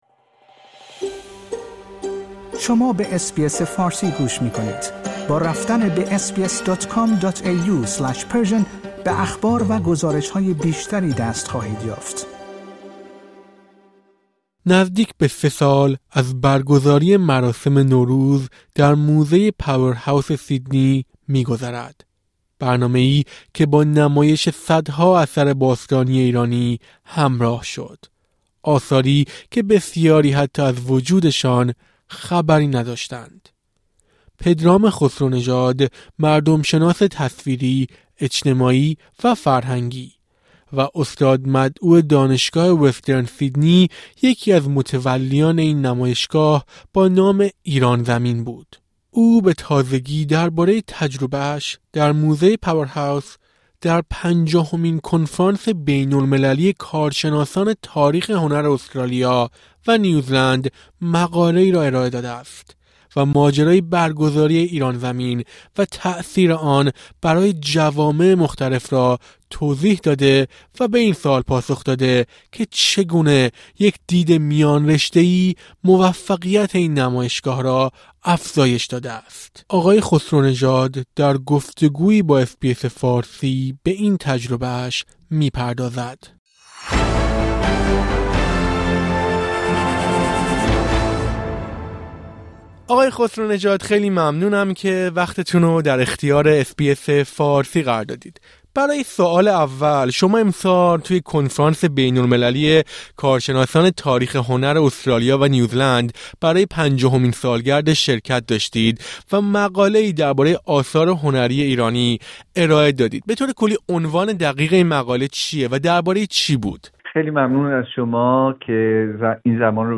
در گفت‌وگویی با اس‌بی‌اس فارسی از بیش از هزار اثر باستانی می‌گوید که در موزه پاورهاوس سیدنی قرار دارند.